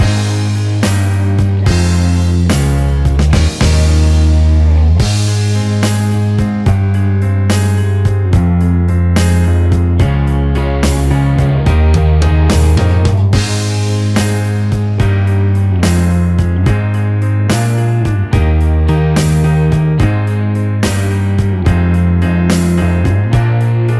no Backing Vocals Rock 4:59 Buy £1.50